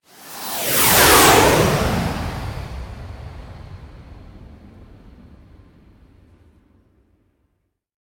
bomb.ogg